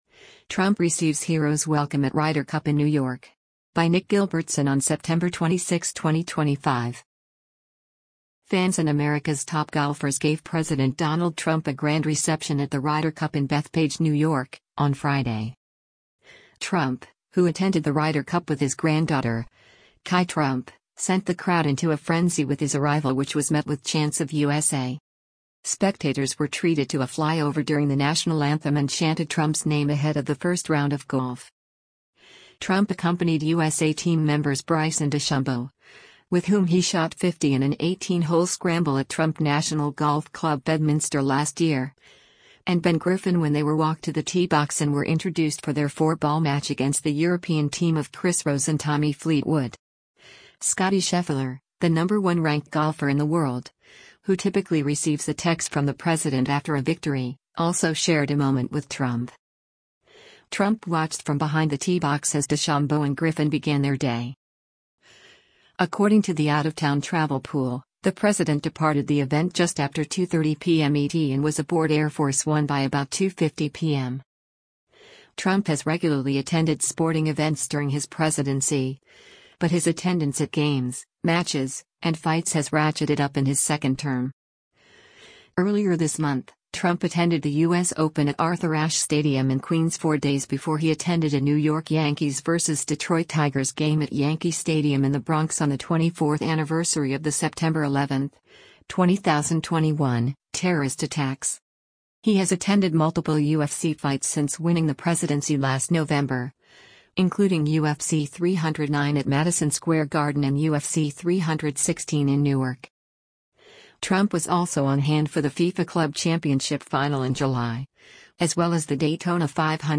Trump, who attended the Ryder Cup with his granddaughter, Kai Trump, sent the crowd into a frenzy with his arrival which was met with chants of “USA!”
Spectators were treated to a flyover during the national anthem and chanted Trump’s name ahead of the first round of golf.